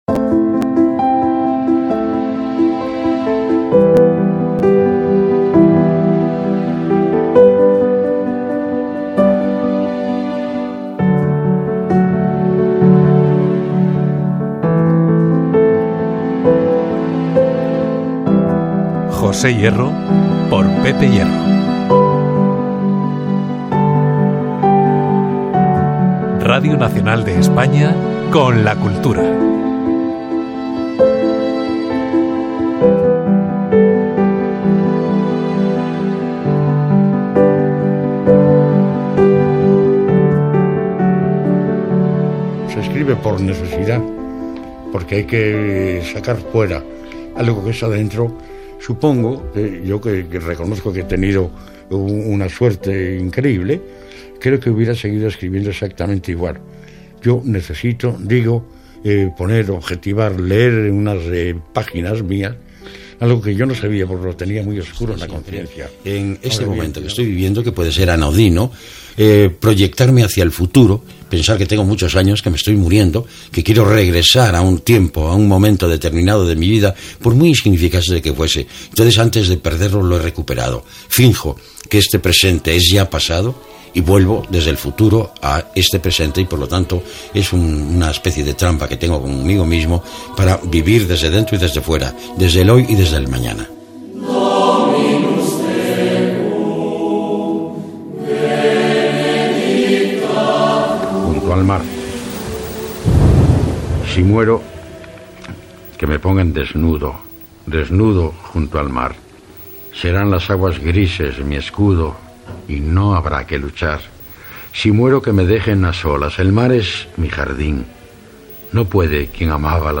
Programa especial dedicat al poeta José Hierro. Careta del programa, recull d'opinions i explicacions del protagonista. Lectura d'un poema seu